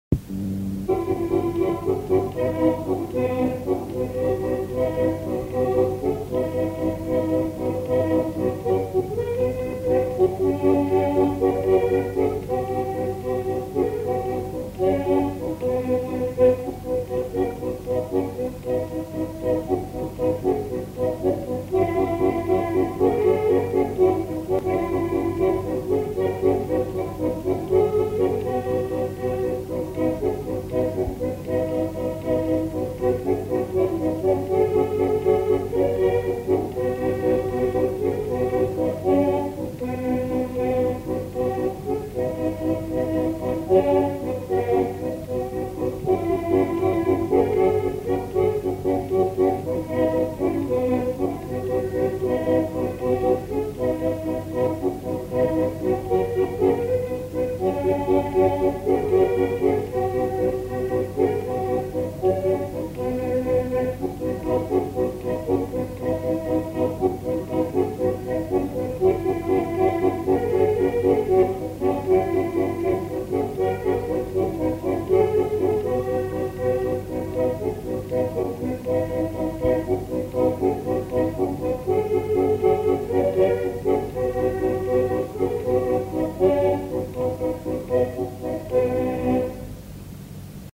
Aire culturelle : Haut-Agenais
Lieu : Monclar d'Agenais
Genre : morceau instrumental
Instrument de musique : accordéon diatonique
Danse : valse